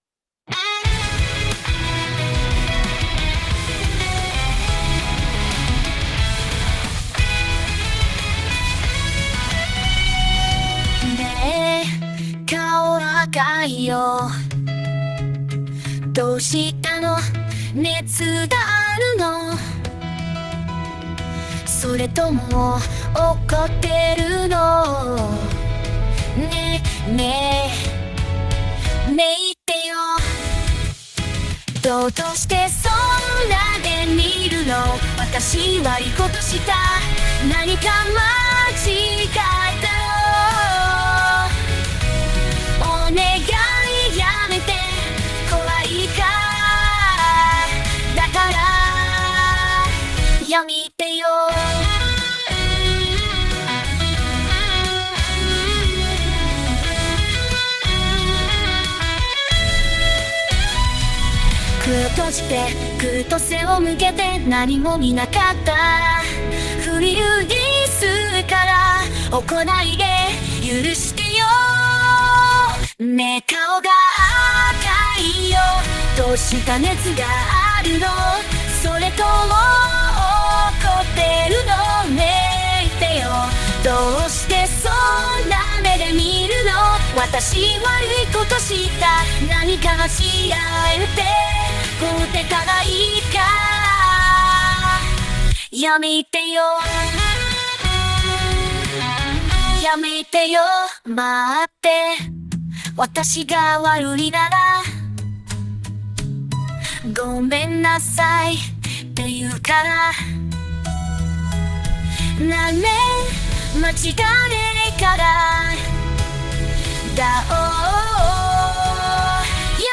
Shit sounds robotic compared to it.
This is AceStep
Yeah, it's not actually there.
jpop.opus